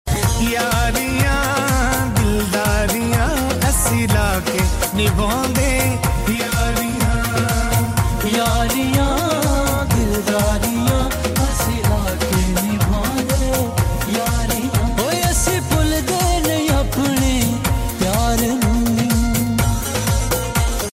Qawali night Grace college khanpur